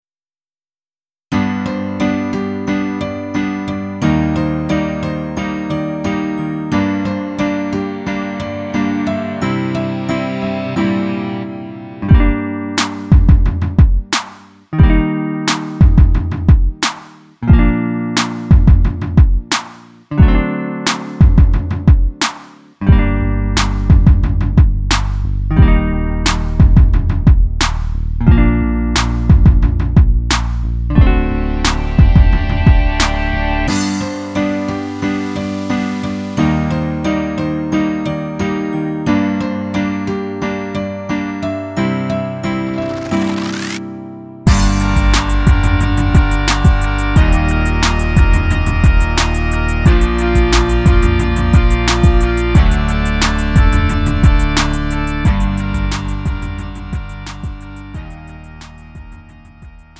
음정 원키 2:51
장르 가요 구분